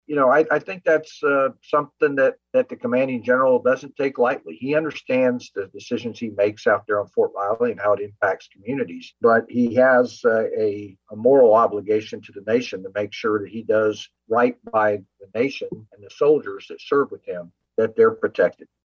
Wiggins says vaccine mandates are not a new concept for troops.